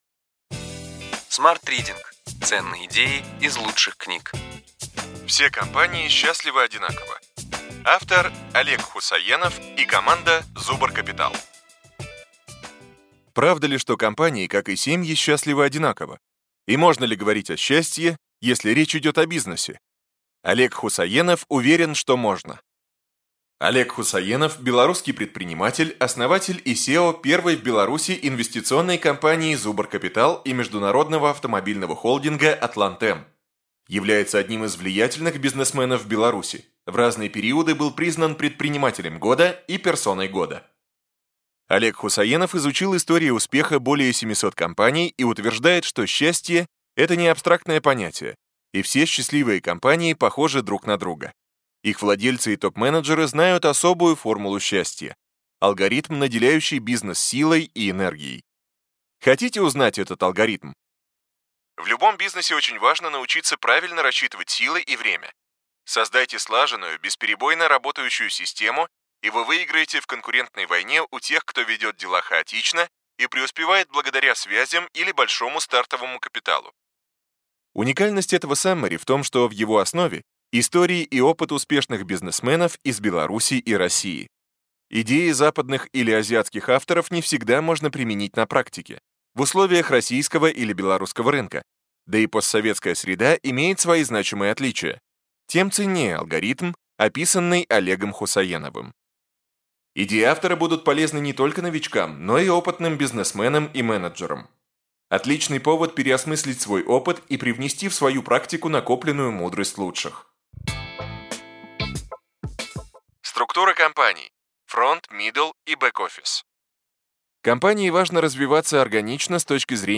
Аудиокнига Ключевые идеи книги: Все компании счастливы одинаково.